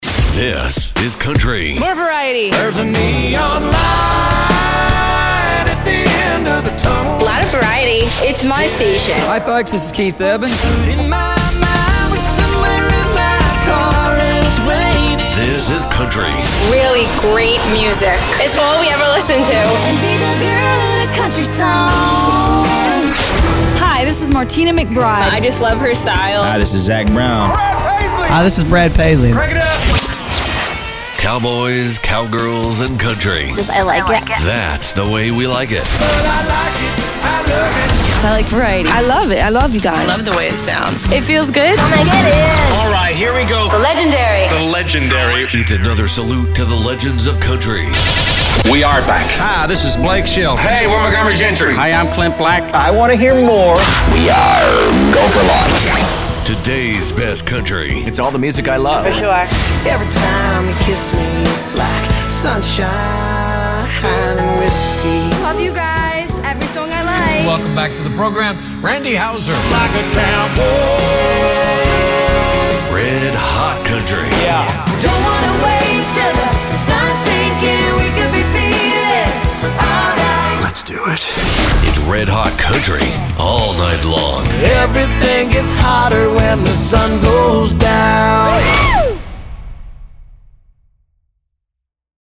COUNTRY Mainstream
Its big and bold and has all the production elements you need at the touch of a button.